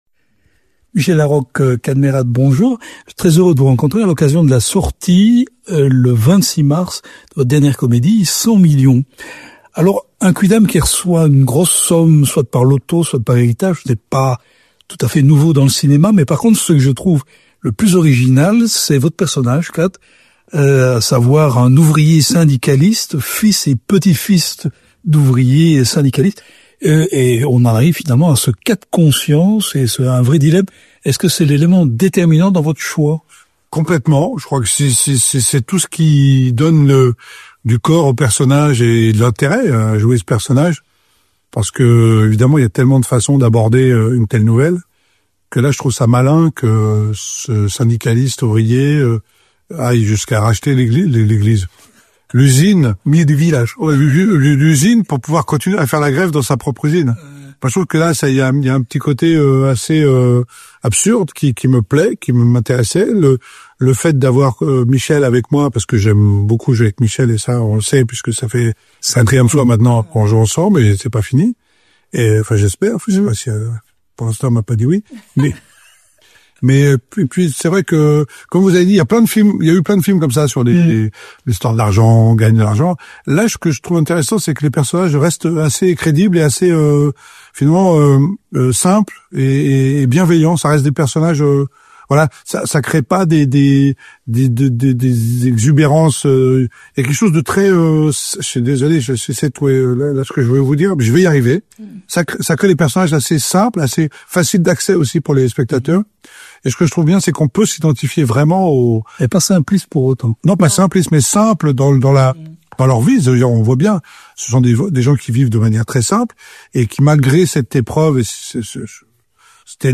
Rencontre. On y parle de Molière, de Cuba, d’empathie, d’impro, de Brillantissime, de Costa-Gavras…« 100